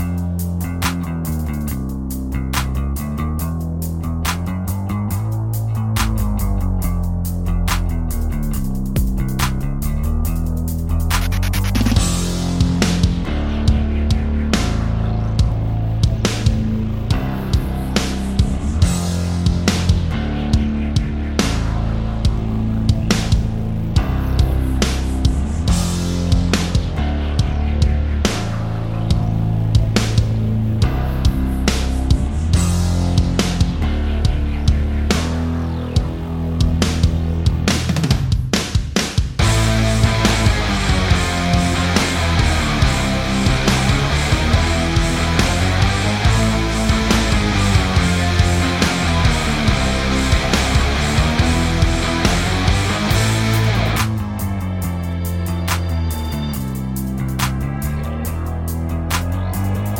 no Backing Vocals Indie / Alternative 3:26 Buy £1.50